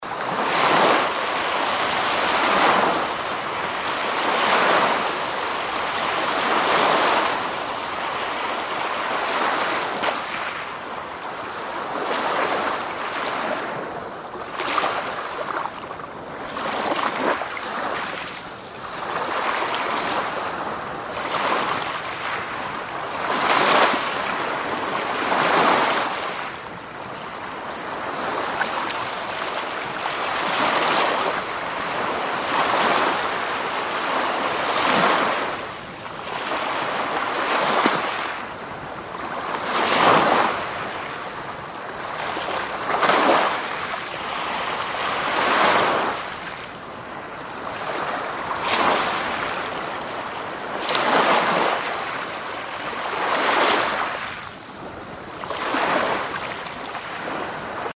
Tiếng Sóng Biển vỗ bờ
Thể loại: Tiếng thiên nhiên
tieng-song-bien-vo-bo-www_tiengdong_com.mp3